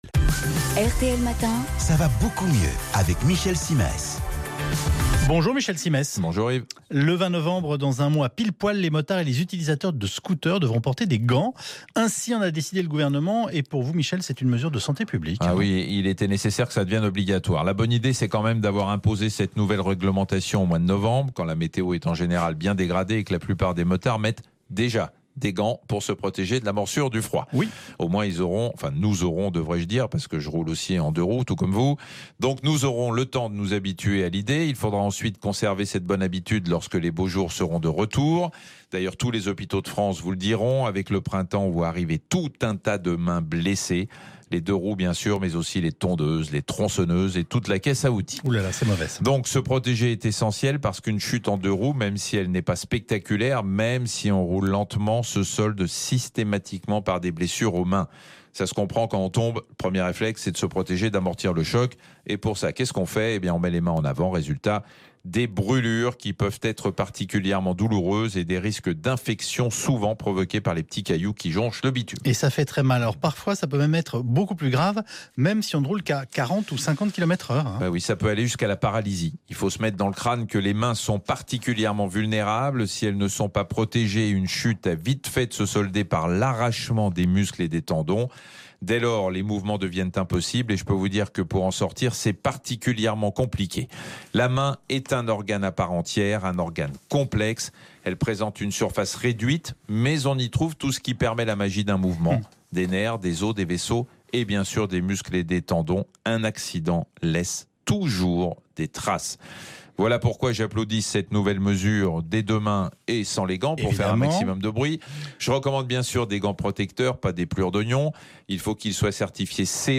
Un synthèse audio sous forme de podcast sur l’intérêt et l’obligation du port des gants moto en deux roues, vu par le docteur Michel Cymés.
michel-cymes-conducteurs-de-deux-roues-mettez-des-gants-rtl-rtl.mp3